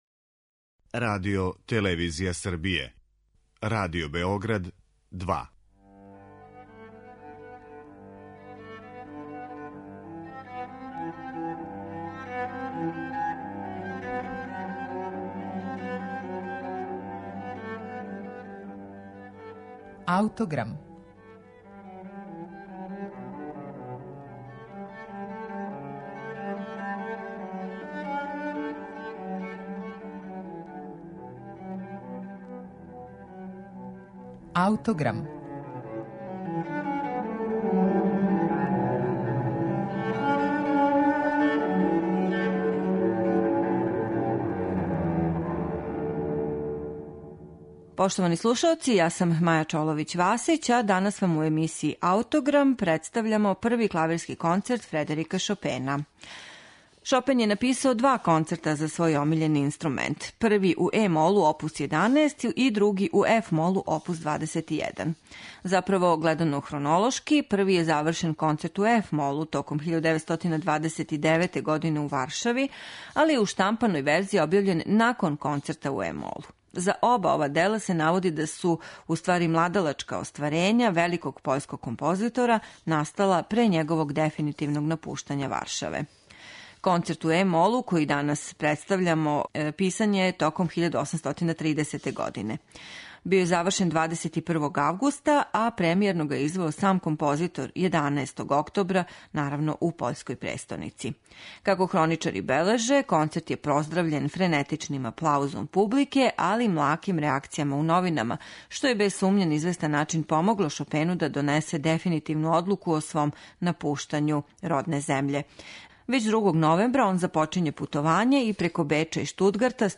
Емисија је посвећена Првом клавирском концерту Фредерика Шопена oп. 11 у е-молу.